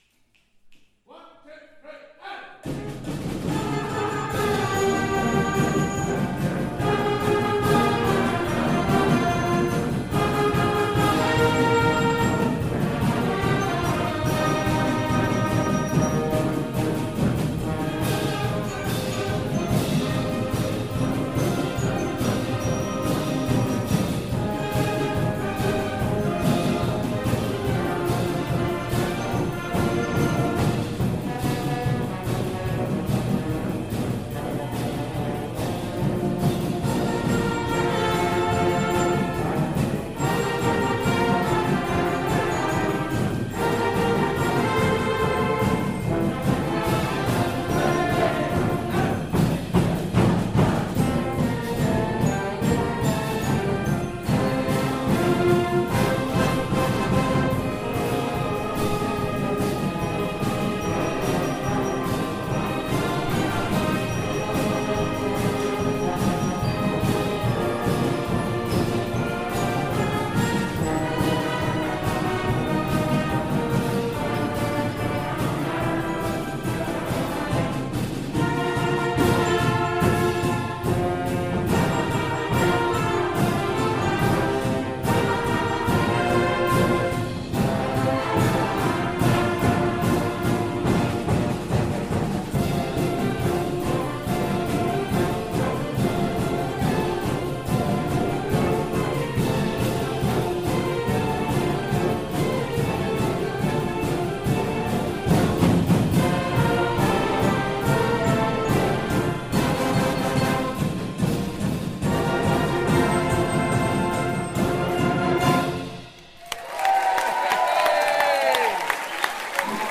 Wind and Brass Concert April 2018 Second Half